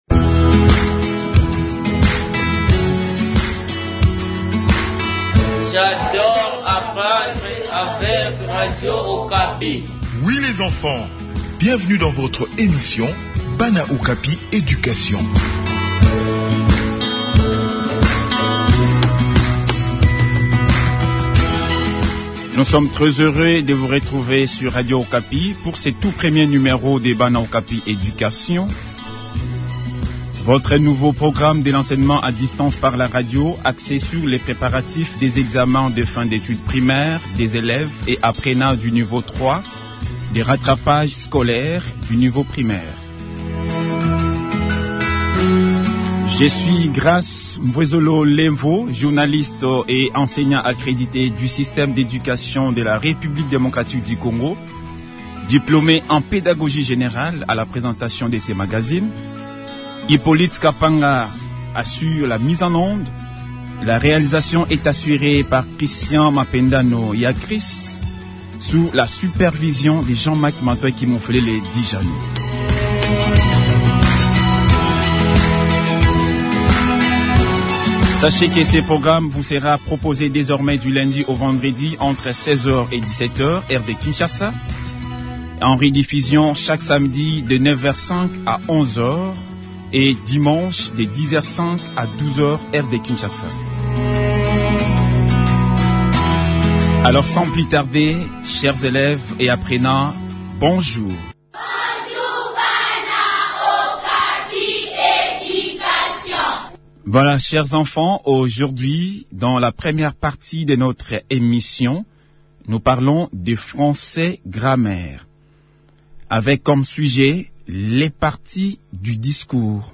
Chaque jour du lundi au vendredi, plusieurs leçons importantes et intéressantes du programme scolaire seront proposées en direct à la radio entre 16h-17h (heure de Kinshasa) et accessibles sur les pages sociales BanaOkapi.